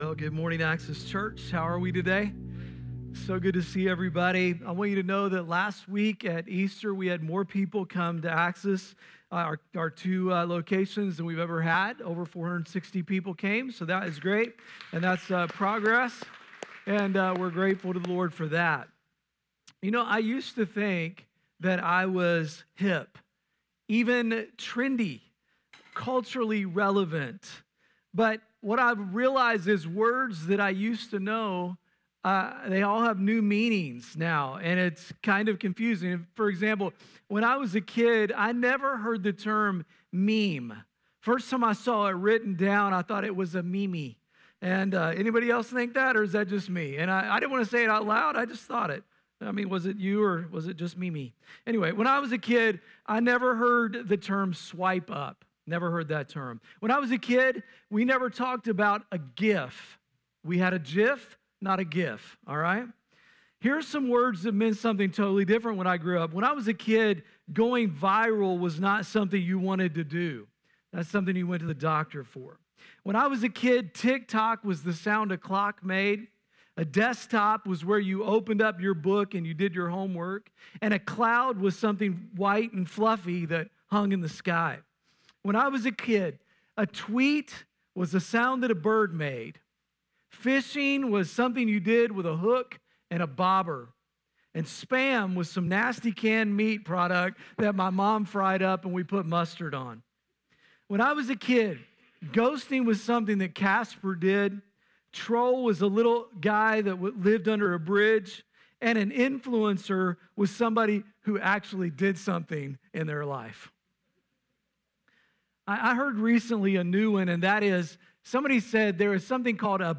Listen or watch on-demand to the weekly message.